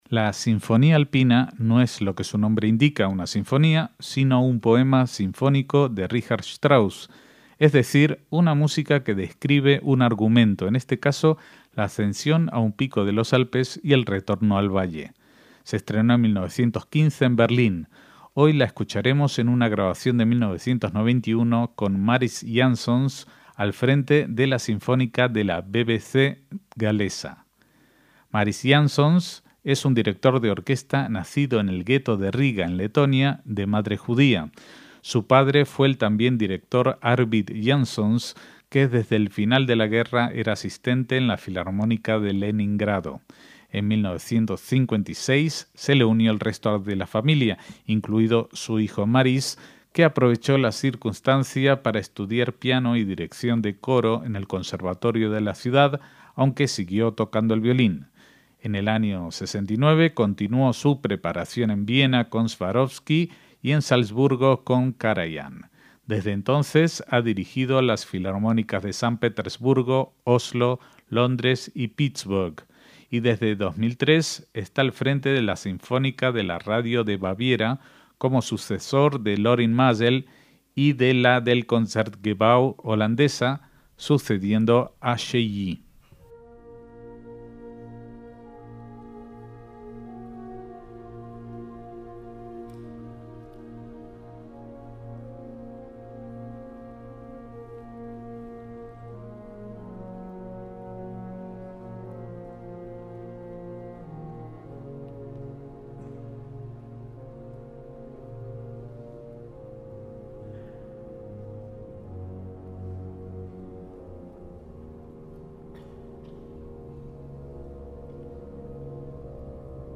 Mariss Jansons dirige Una Sinfonía Alpina de Richard Strauss